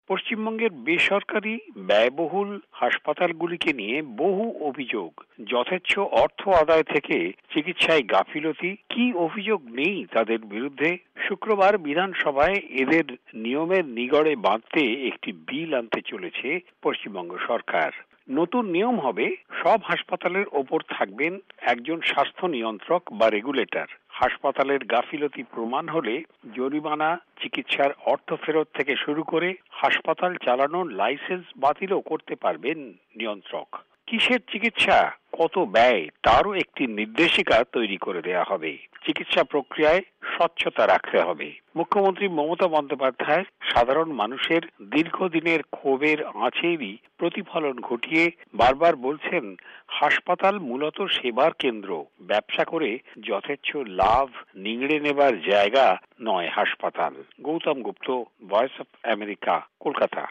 রিপোর্ট